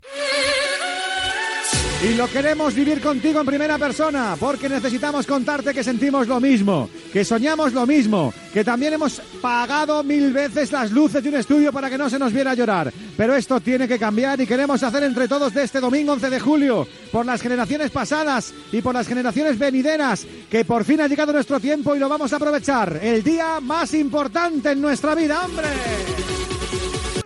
Transmissió de la final de la Copa del Món de futbol masculí entre les seleccions d'Espanya i Països Baixos al Soccer City de Johannesburg, Sud-àfrica.
Introducció del programa.
Esportiu